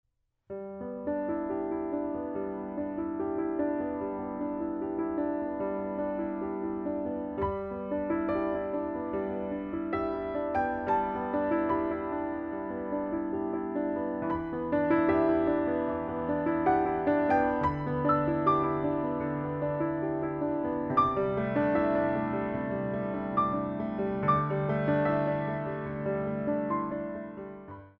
4 Count introduction included for all selections
4/4 - 64 with repeat